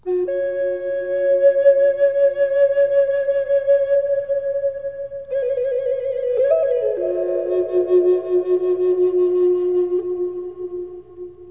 flute music